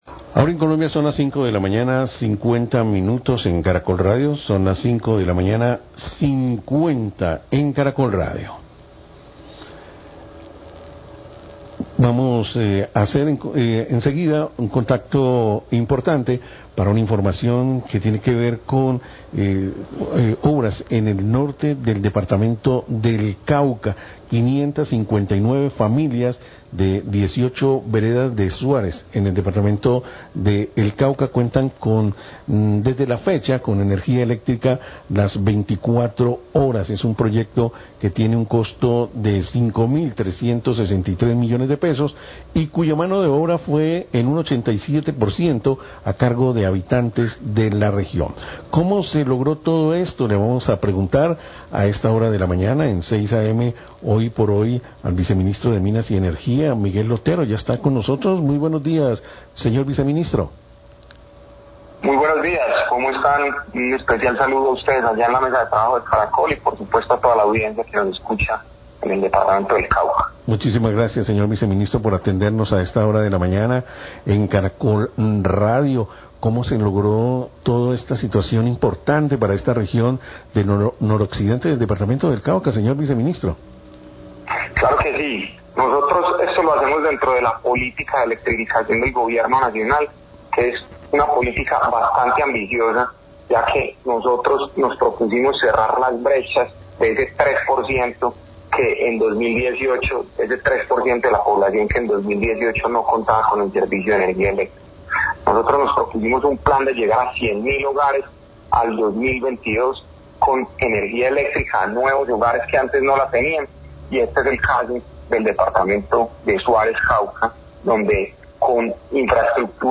Viceministro de Energía habla del proyecto de electrificación en zona rural de Suárez,
Radio
El Viceministro de Minas y Energía acompañó Gobernador del Cauca, a la Directora del Fondo Todos Somos Pazcífico, al Alcalde de Suárez y a Directivos de la Compañía Energética y comunidad entregando el servicio de energía eléctrica en Suárez, se llegó a 559 hogares con recursos del fondo de Todos Somos Pazcífco y de Regalías. Declaraciones del funcionario.